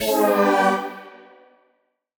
Index of /musicradar/future-rave-samples/Poly Chord Hits/Ramp Down
FR_T-PAD[dwn]-G.wav